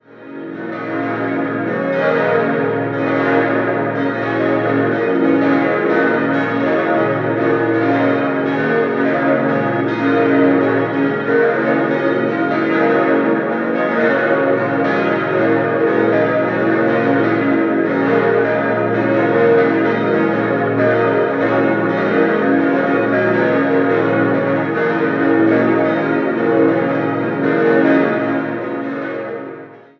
6-stimmiges Geläute: b°-des'-es'-f'-as'-b'
Alle Glocken wurden im Jahr 2016 von der Gießerei Bachert in Karlsruhe gegossen.
Heutiges Geläut (seit 2016)